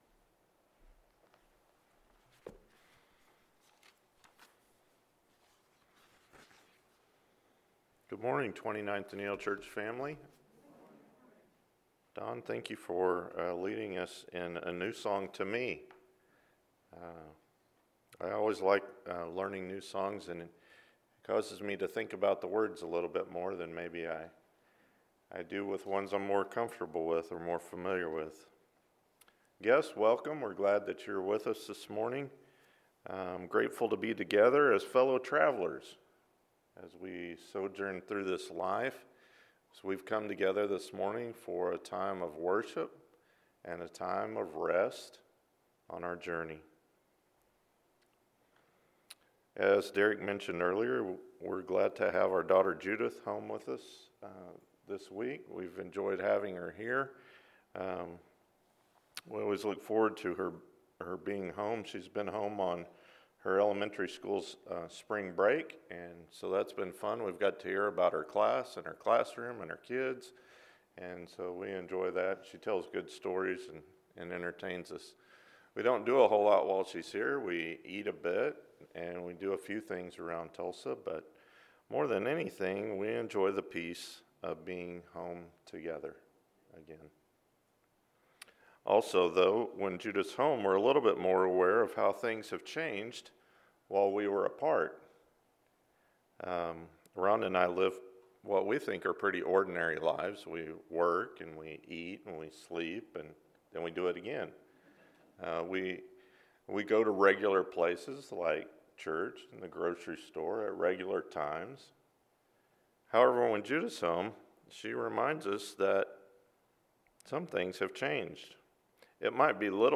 On the Road Together with the Exiles – Sermon